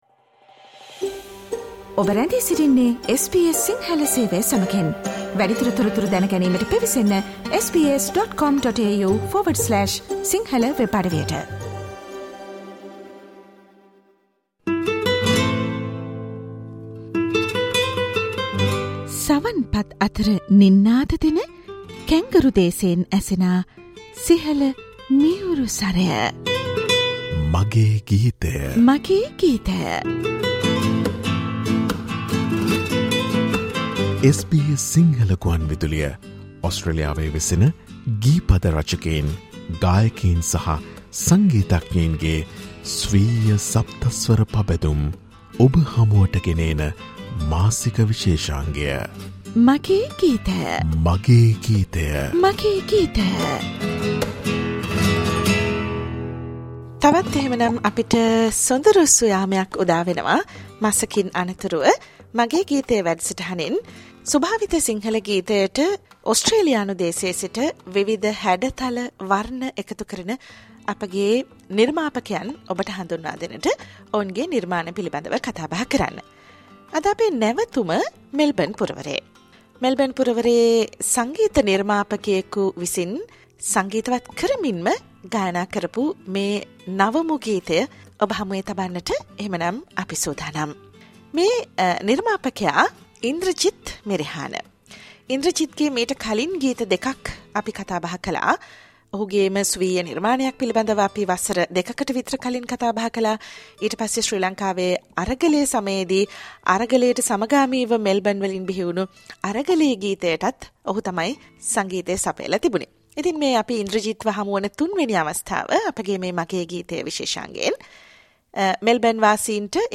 SBS Sinhala monthly musical program